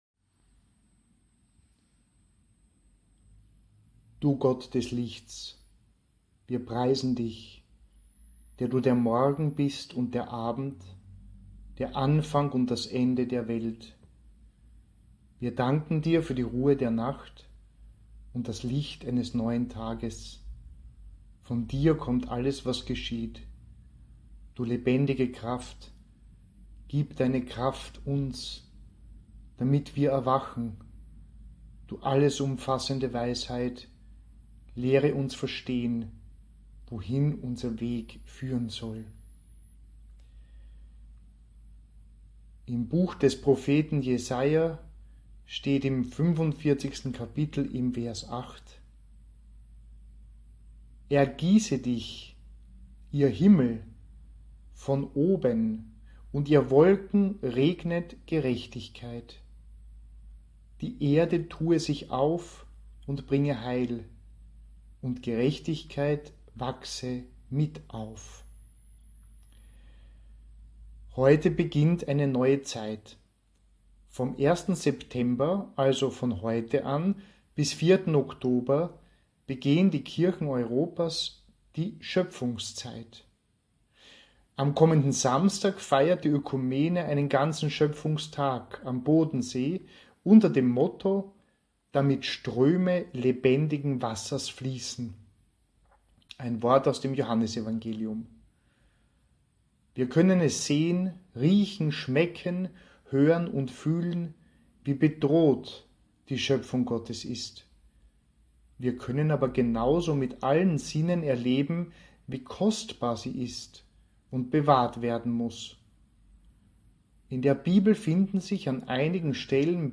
Seit den sehr restriktiven Maßnahmen zur Eindämmung der Covid-19 Pandemie haben wir Audio-Minutenandachten gestaltet und aufgenommen.
Minutenandacht für September